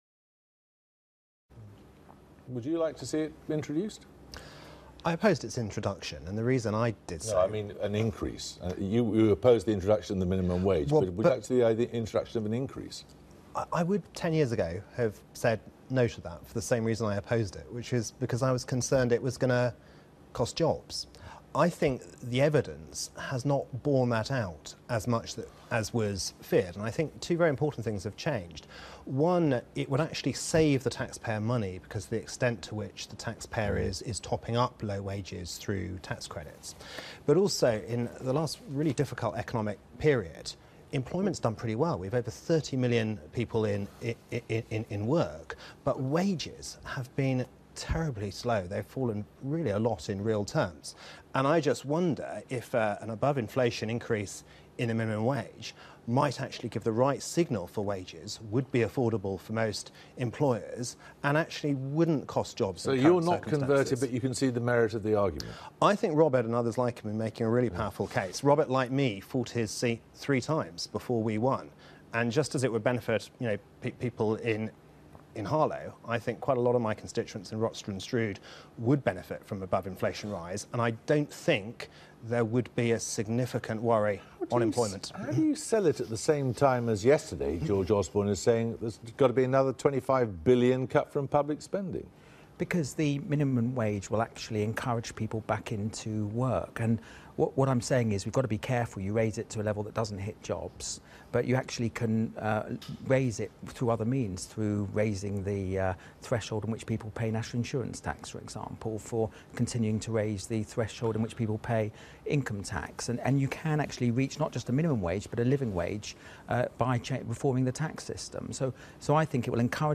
Mark Reckless discusses the minimum wage with Robert Halfon on Newsnight.